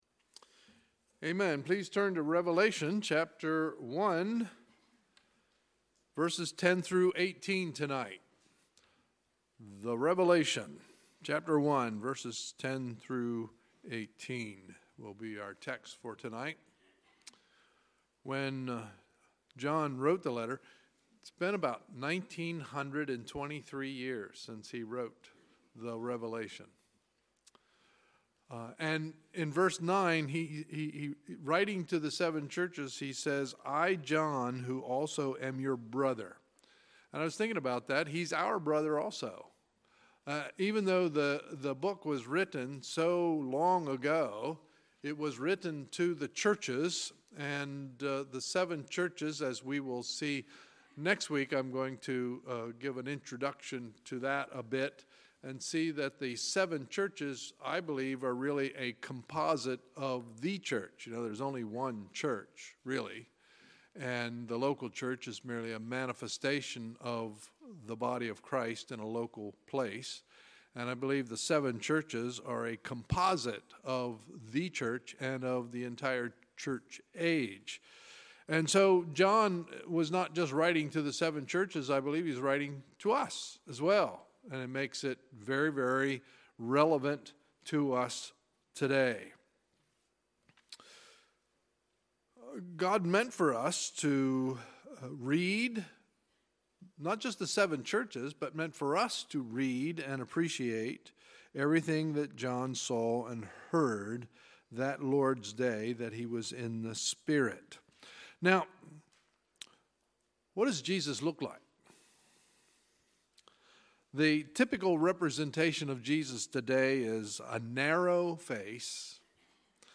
Sunday, January 28, 2018 – Sunday Evening Service
Sermons